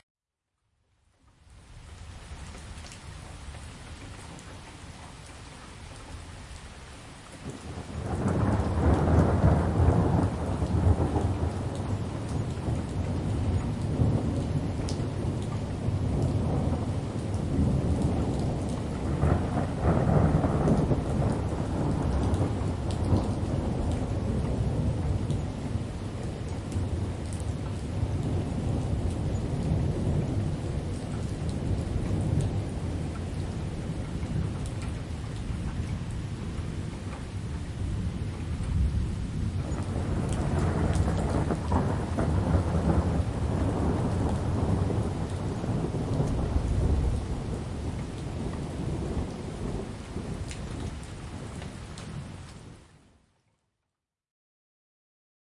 自然的声音 " 有小雷雨的午后
描述：从远处记录雷暴。
Tag: 风暴 下午 天气 闪电